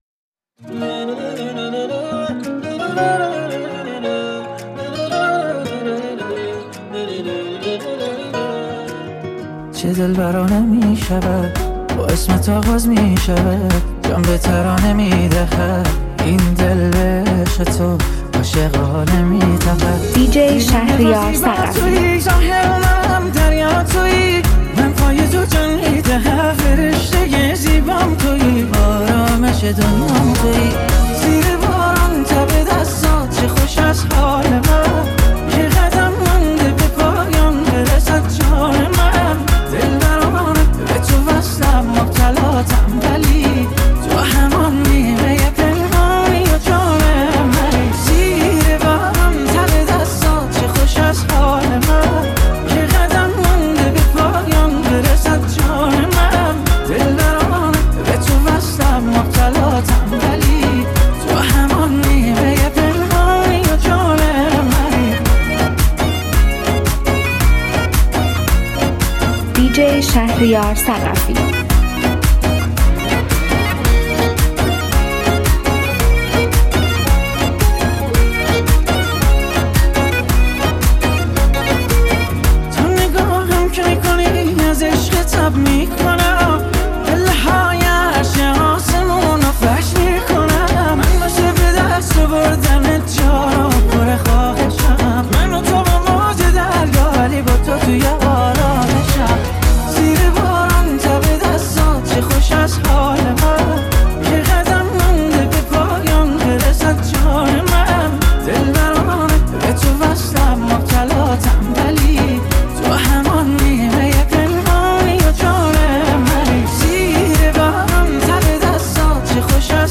دنس